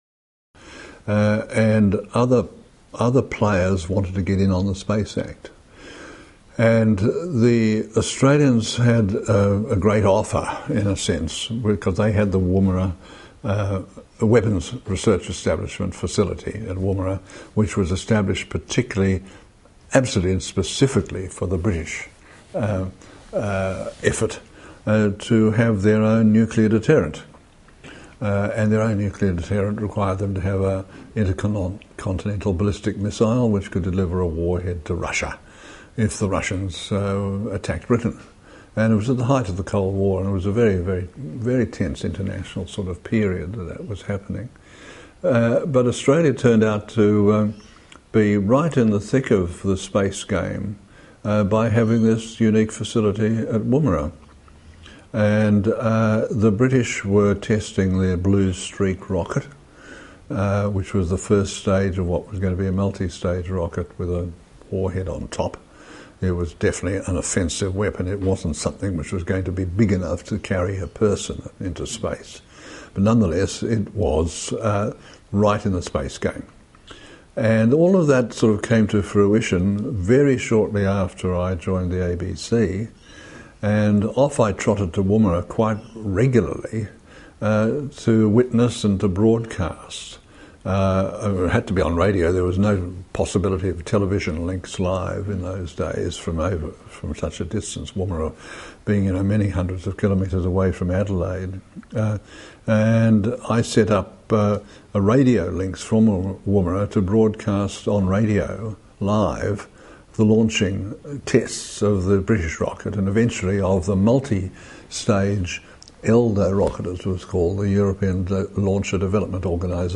In this excerpt from his 2010 interview, he speaks about the challenges of covering the Blue Streak and WRESAT launches from the outback. Listen to a 10 minute excerpt from the interview 3.9 MB mp3 file.